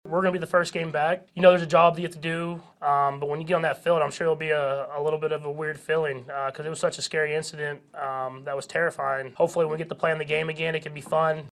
Chiefs quarterback Patrick Mahomes says that Kansas City will have Damar Hamlin on their mind when they play again.